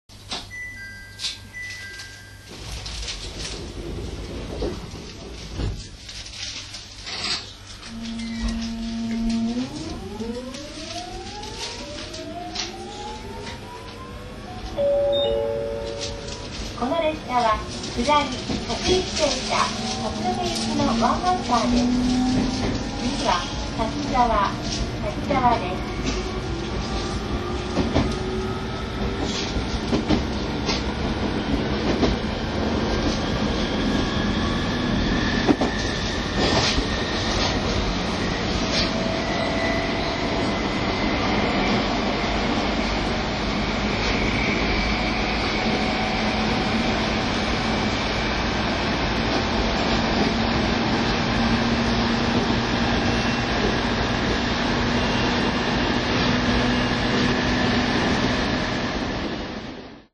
ＩＧＲいわて銀河鉄道IGR7000系電車の加速音（厨川〜滝沢）